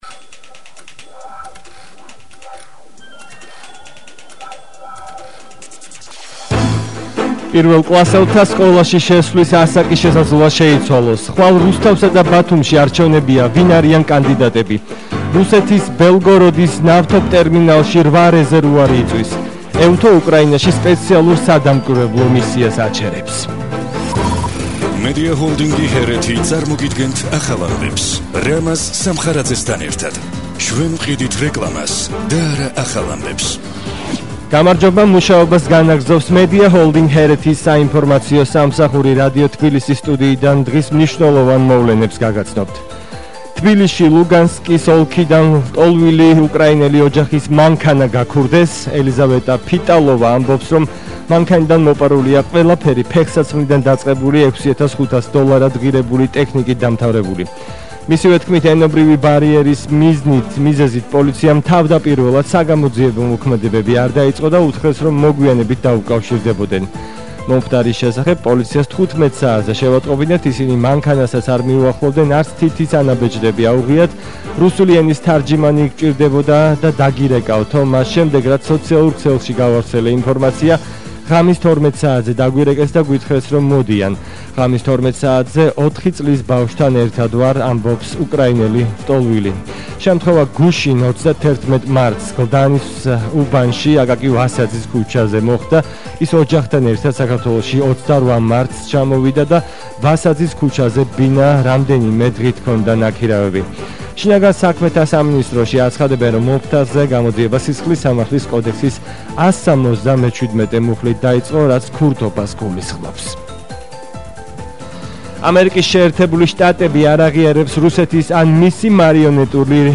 ახალი ამბები 14:00 საათზე – 1/04/22 - HeretiFM